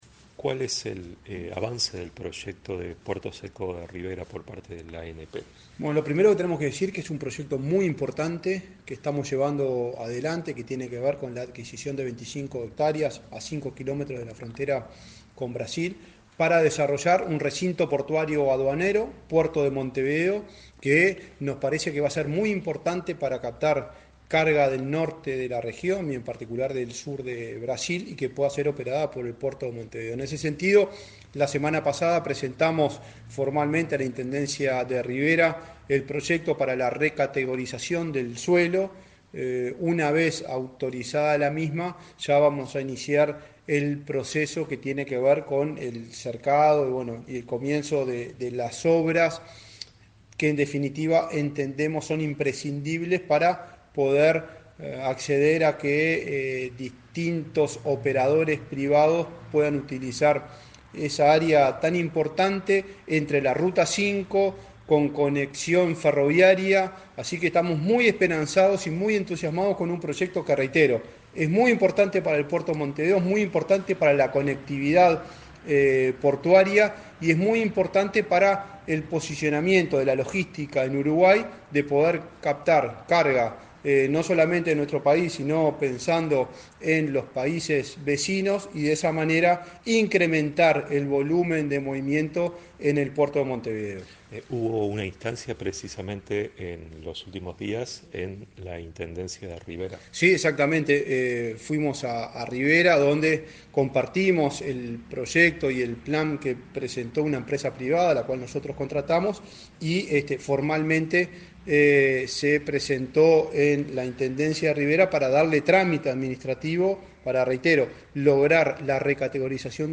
Entrevista al presidente de la ANP, Juan Curbelo